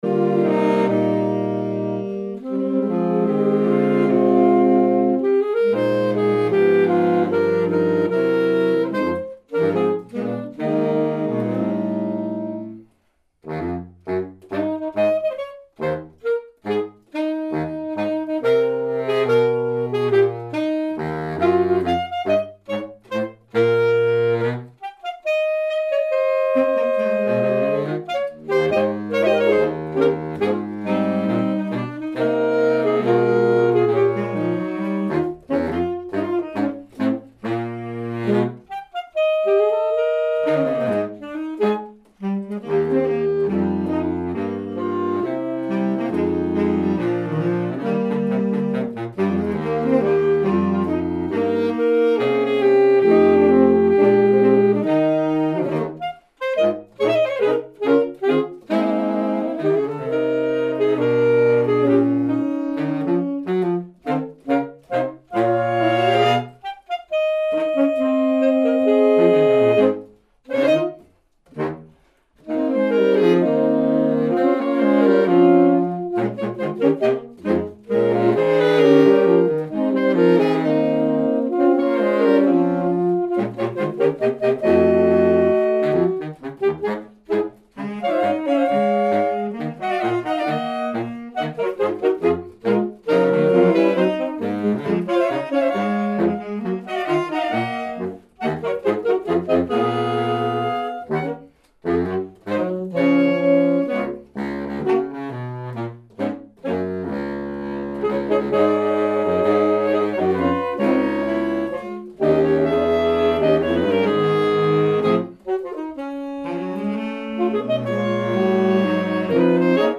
Der Klang ist wärmer und dumpfer:
Holzsaxophon